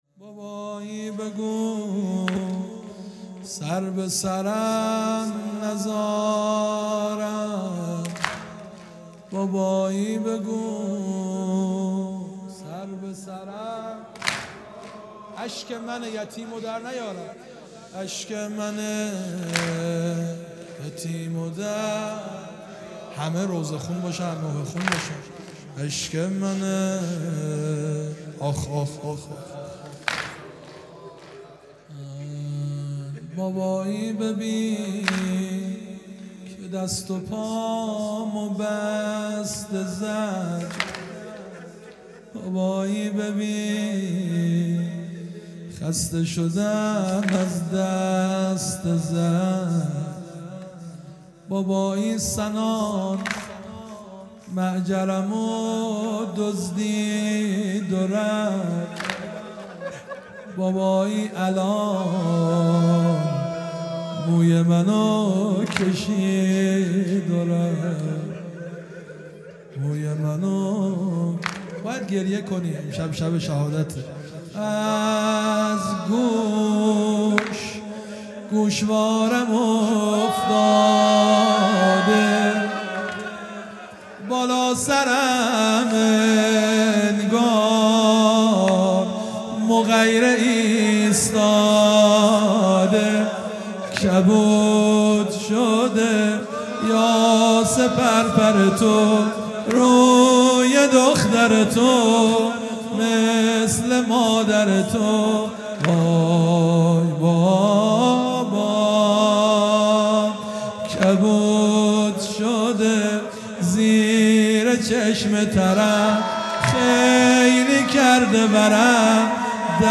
شهادت حضرت رقیه (س) | ۳ آبان ۱۳۹۶